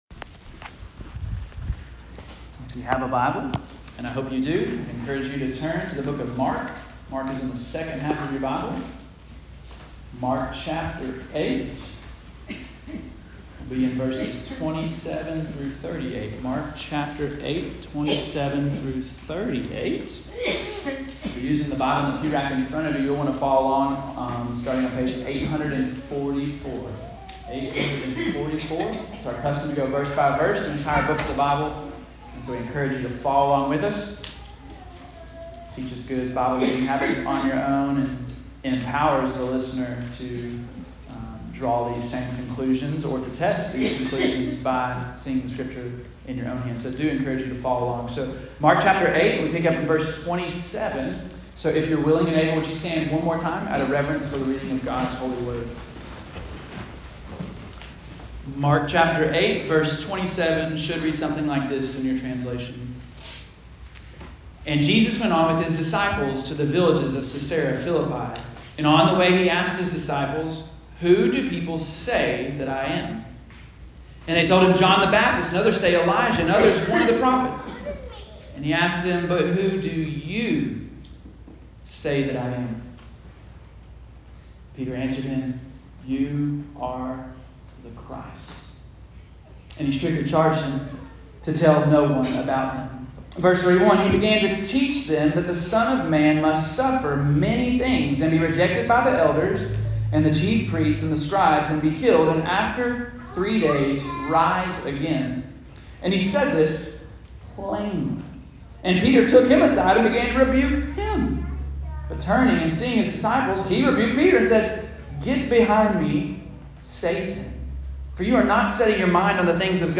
Bethany Baptist Church Listen to Sermons